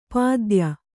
♪ pādya